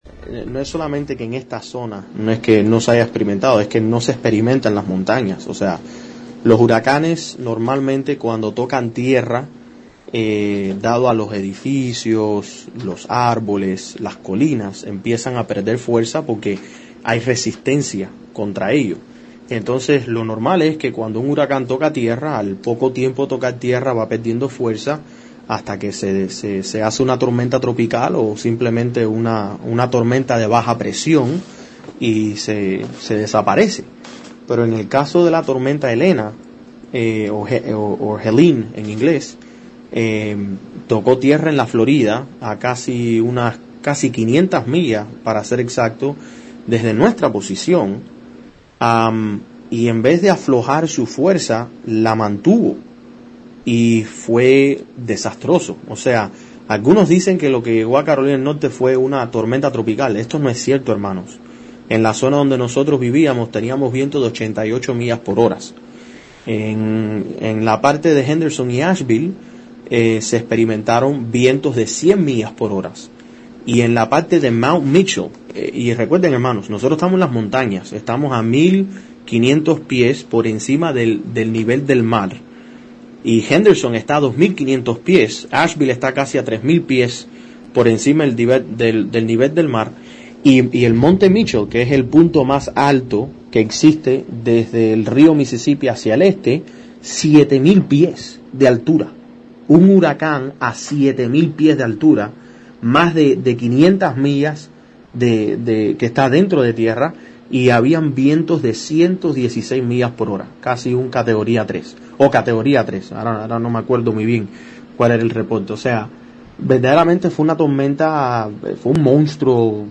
Periodista-cubano-huracan.mp3